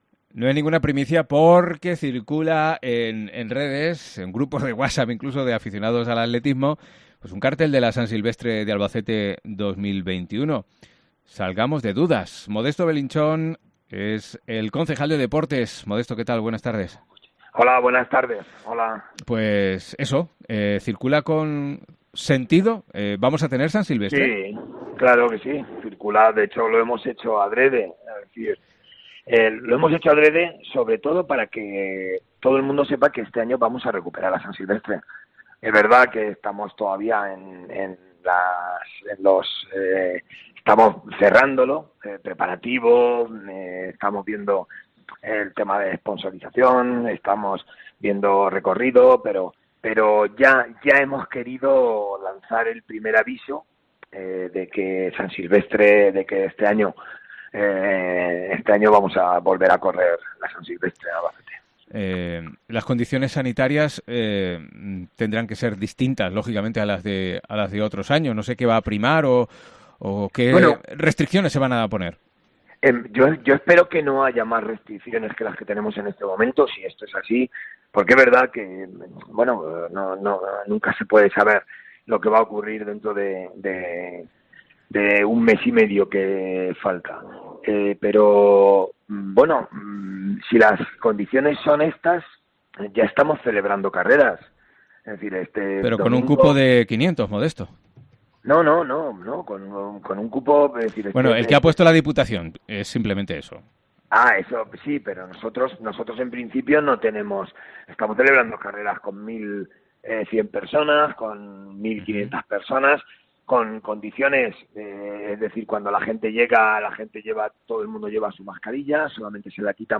AUDIO: Nos lo confirma Modesto Belinchón, concejal de Deportes, aunque el recorrido y algunos otros detalles están en estudio